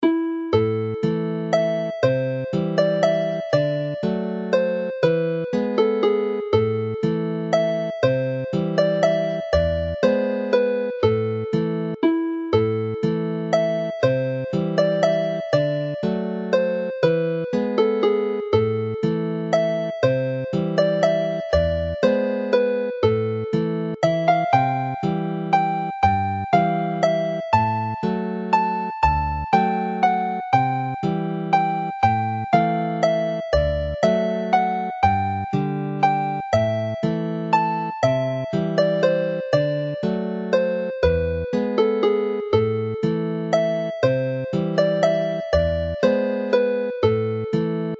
Alawon Cymreig - Set Blodau'r Drain- Welsh folk tunes to play
All three melodies are in the Welsh A minor which use a G# in the scale, giving an E major chord rather than the E minor generally found in Irish and Scottish tunes.